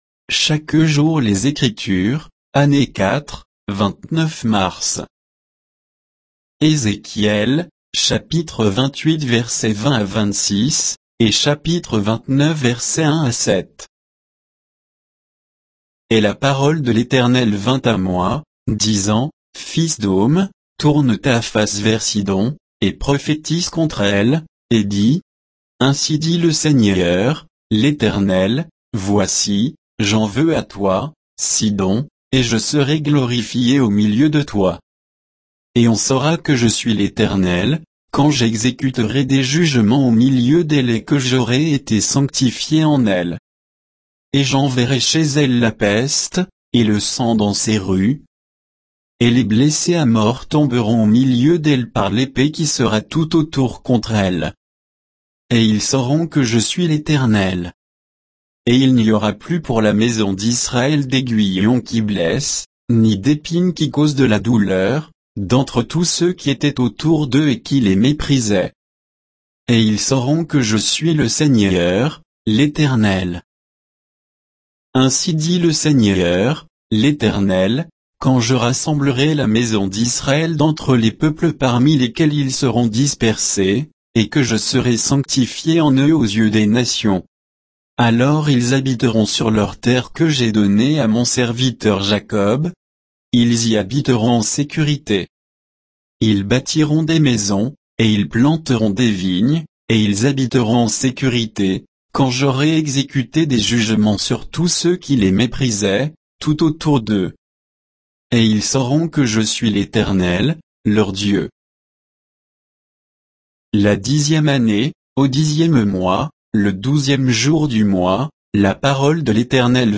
Méditation quoditienne de Chaque jour les Écritures sur Ézéchiel 28, 20 à 29, 7